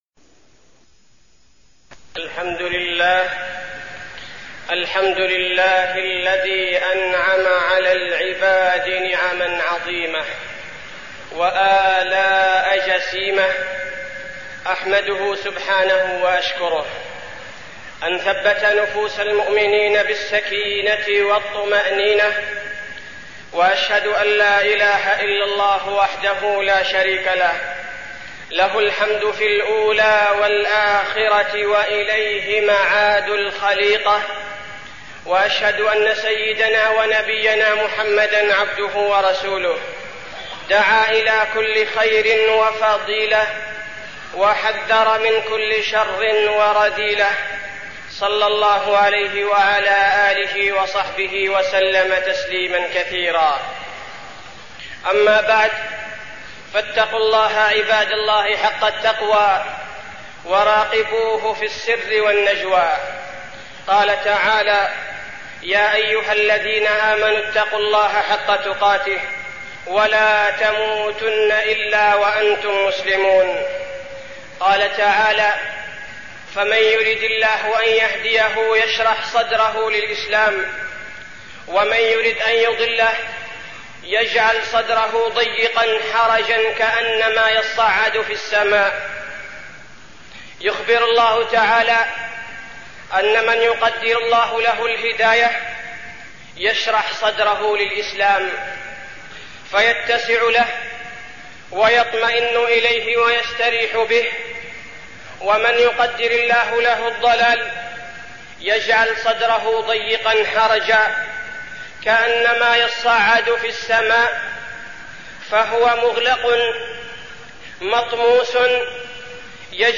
تاريخ النشر ٥ ذو القعدة ١٤١٧ هـ المكان: المسجد النبوي الشيخ: فضيلة الشيخ عبدالباري الثبيتي فضيلة الشيخ عبدالباري الثبيتي نعمة الإسلام